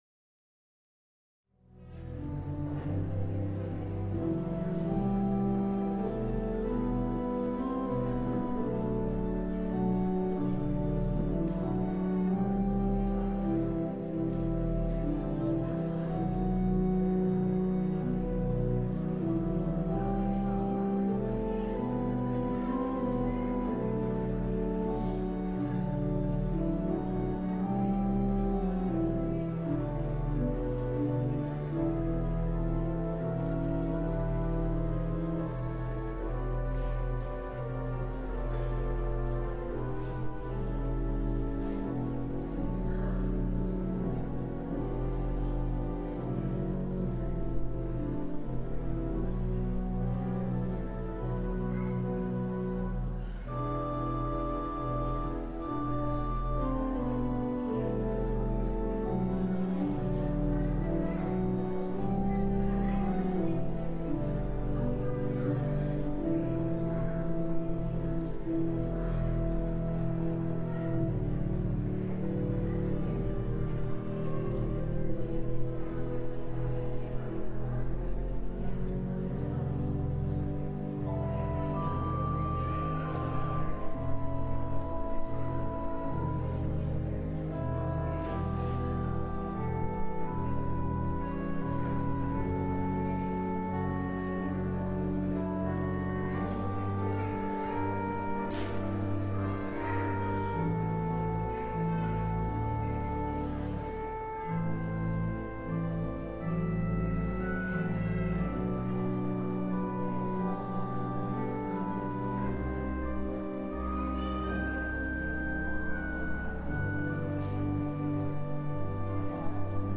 LIVE Afternoon Worship Service - The Children's Christmas Program